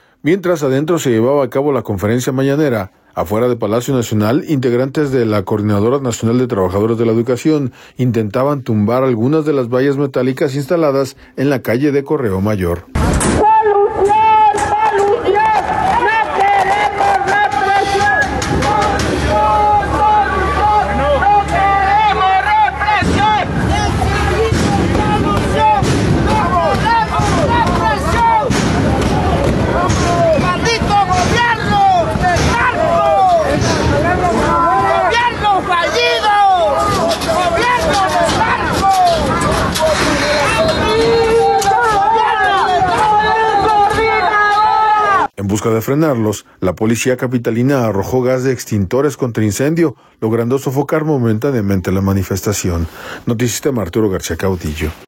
Mientras adentro se llevaba a cabo la conferencia Mañanera, afuera de Palacio Nacional, integrantes de la Coordinadora Nacional de Trabajadores de la Educación intentaba tumbar algunas de las vallas metálicas instaladas en la calle de Correo Mayor.